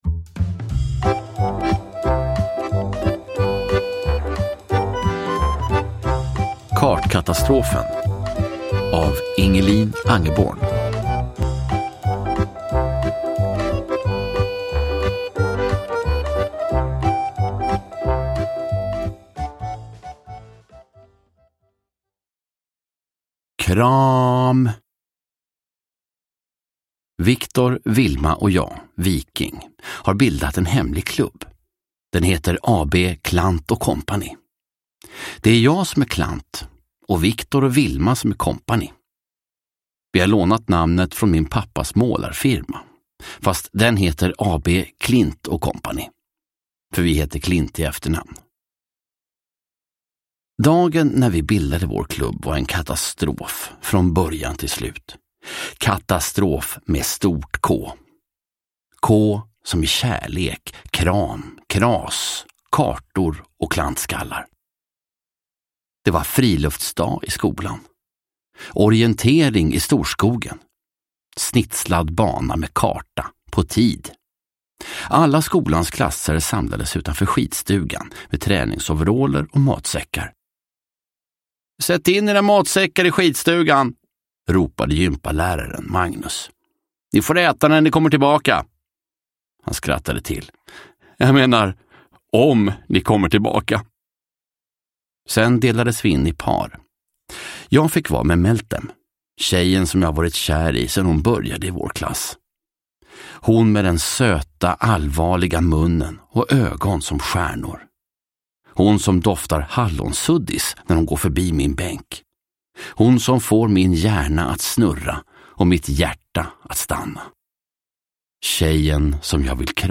Kartkatastrofen – Ljudbok – Laddas ner